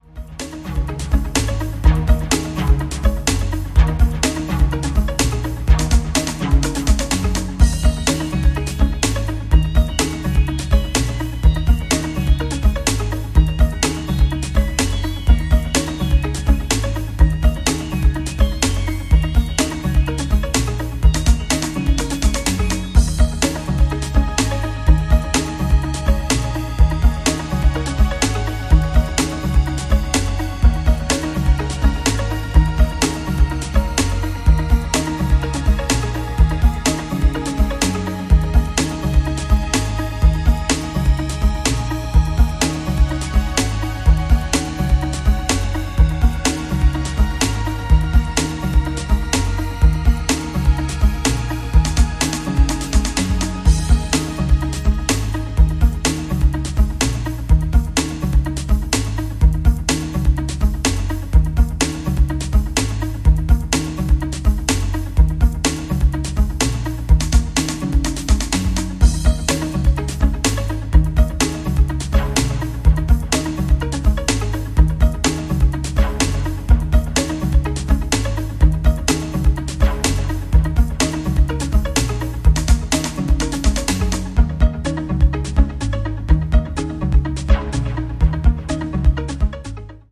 NYよりも当時のUK/EUっぽい空気感が色濃く感じられてきますね。